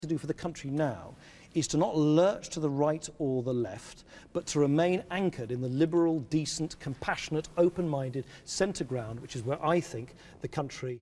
Interview with BBC's Evan Davis